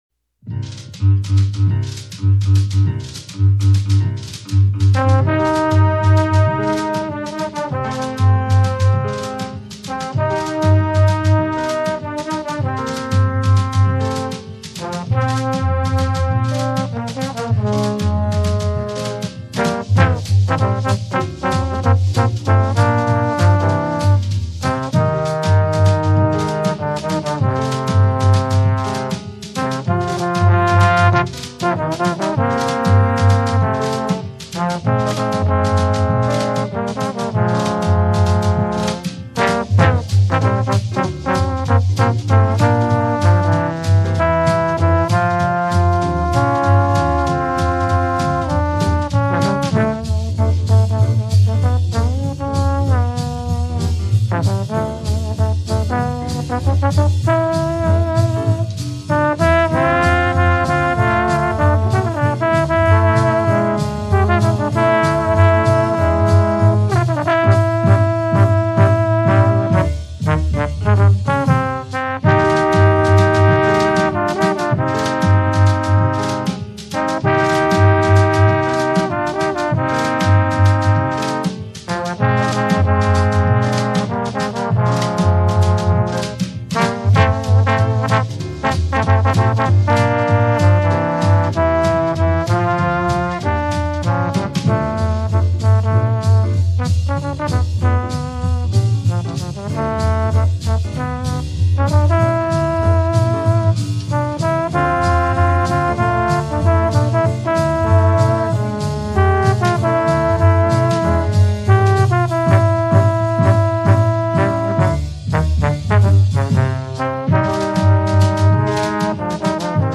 TROMBONE SCORE
RHYTHM SECTION